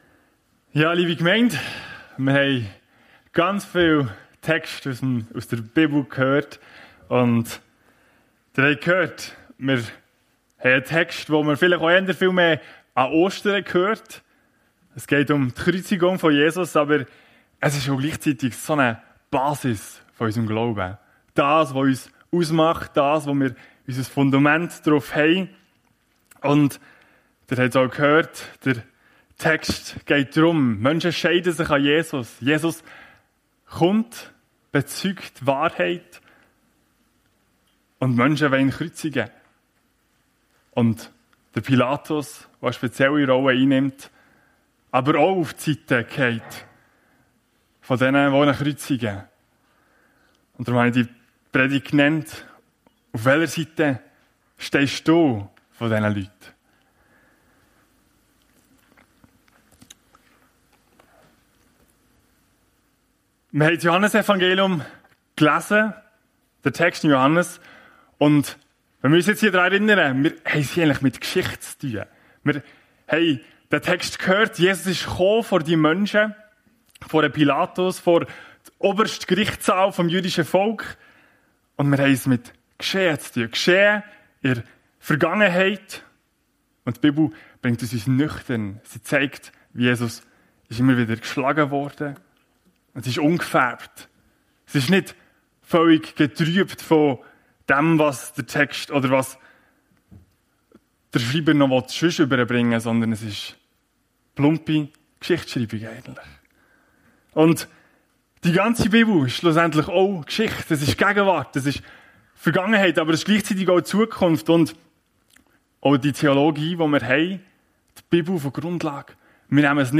Auf welcher Seite stehst du? ~ FEG Sumiswald - Predigten Podcast